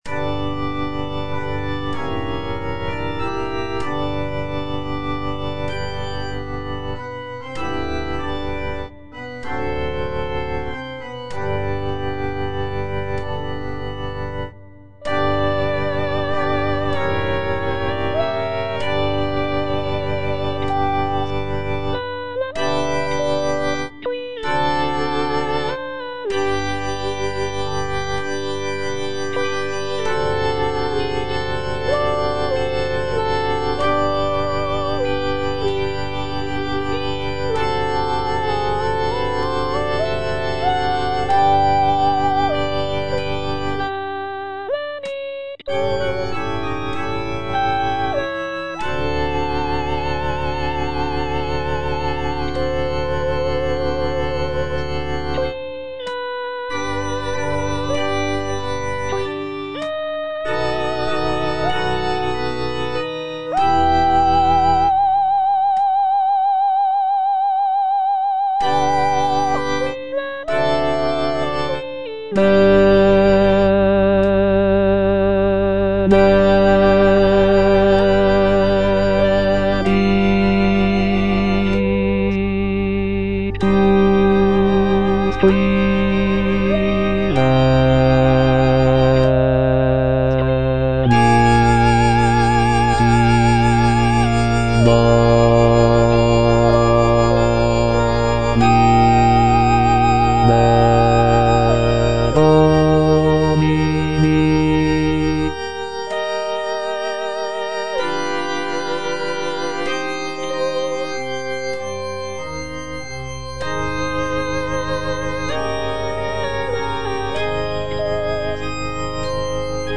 C.M. VON WEBER - MISSA SANCTA NO.1 Benedictus - Bass (Voice with metronome) Ads stop: auto-stop
The work features a grand and powerful sound, with rich harmonies and expressive melodies.